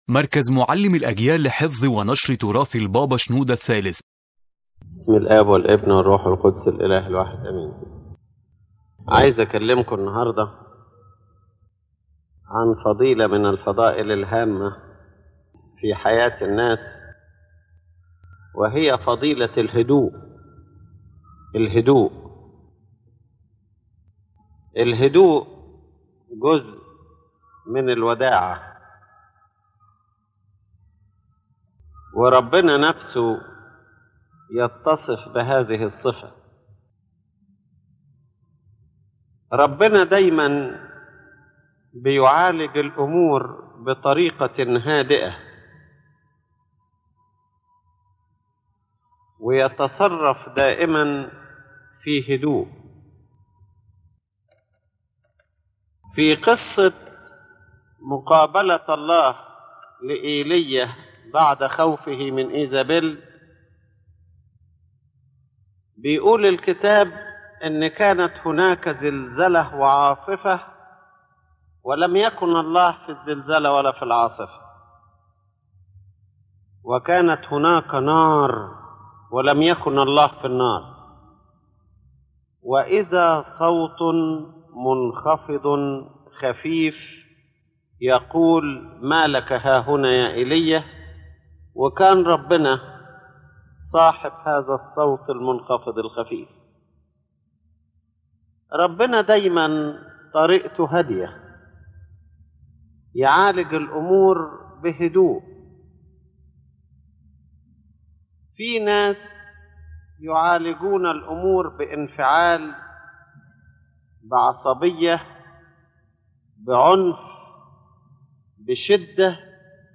The lecture revolves around the virtue of calmness as an essential element in spiritual life and human behavior, showing that calmness is not merely external silence but a deep internal state that includes the heart, mind, and senses.